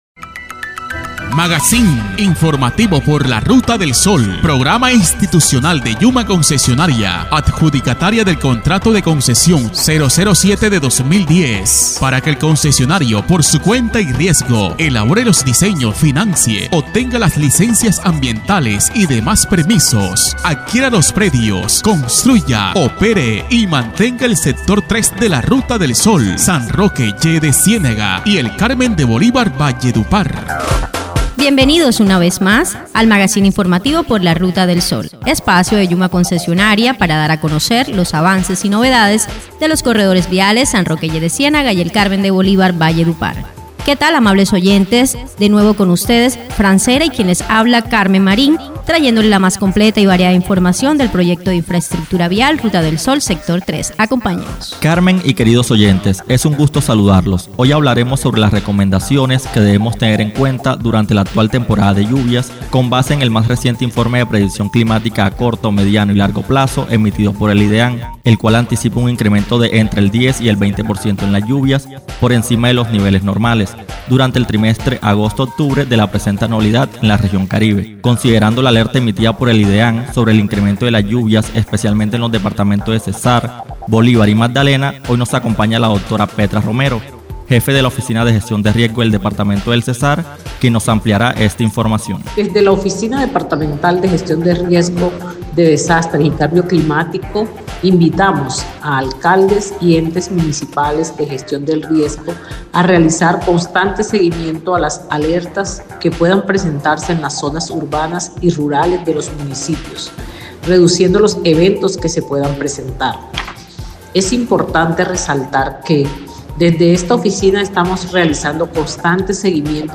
MAGAZÍN INFORMATIVO